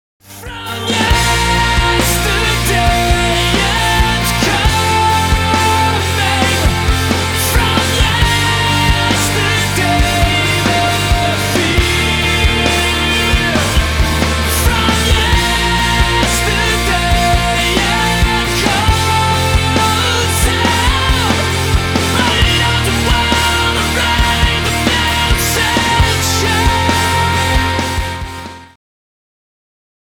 • Качество: 320, Stereo
Alternative Rock
post-grunge
vocal